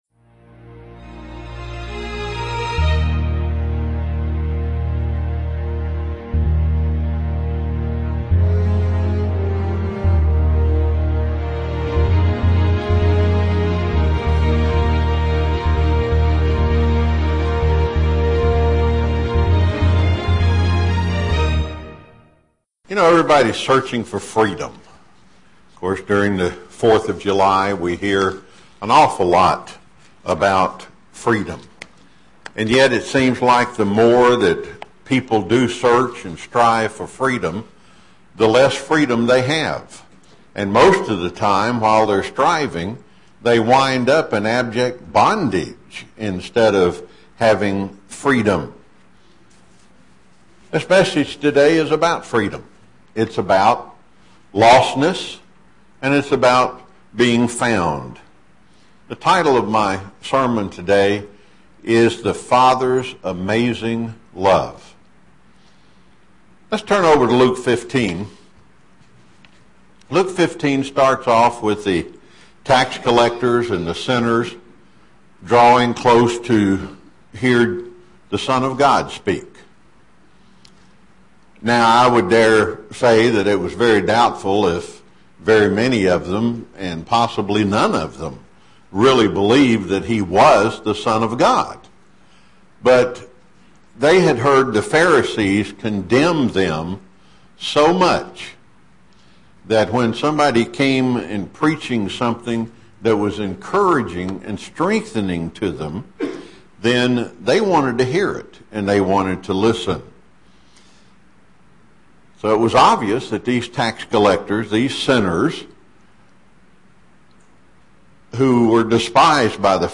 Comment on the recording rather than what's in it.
Given in Chattanooga, TN